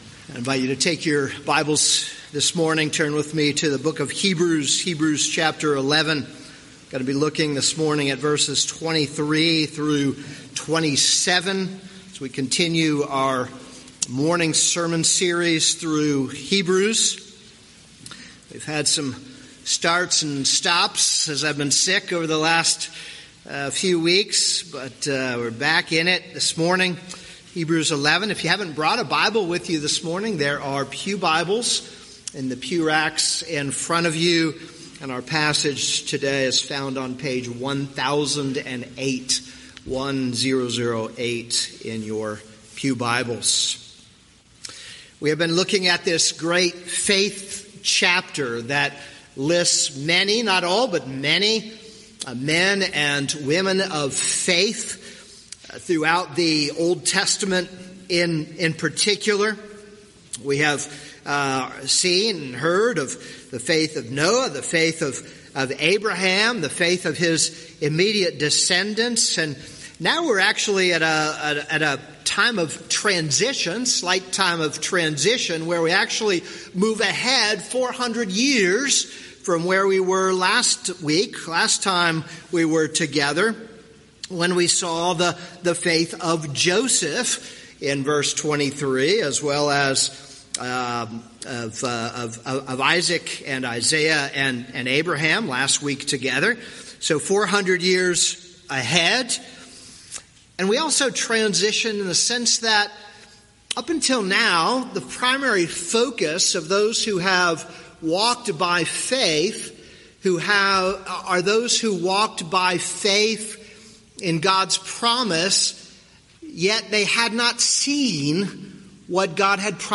This is a sermon on Hebrews 11:23-27.